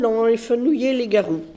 Saint-Hilaire-des-Loges
Catégorie Locution